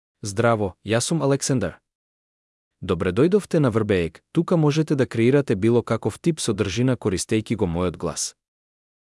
Aleksandar — Male Macedonian AI voice
Voice sample
Male
Aleksandar delivers clear pronunciation with authentic North Macedonia Macedonian intonation, making your content sound professionally produced.